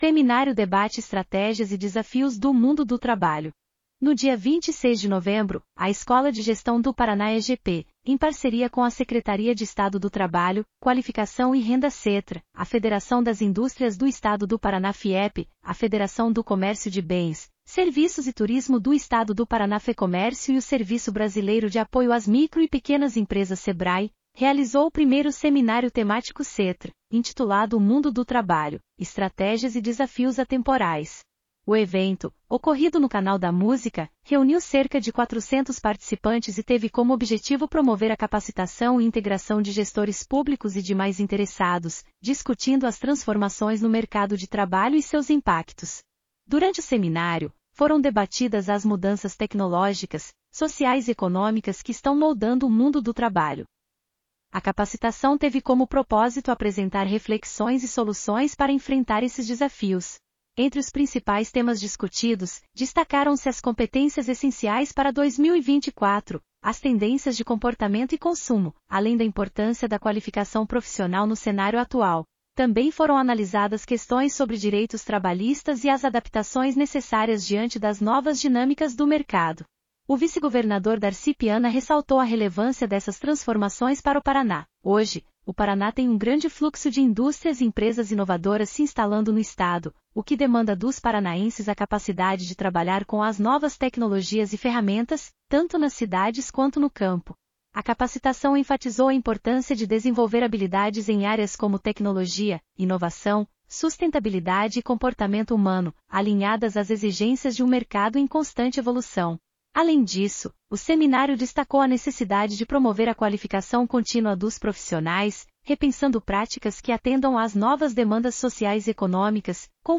audionoticia_seminario_debate_estrategias_e_desafios.mp3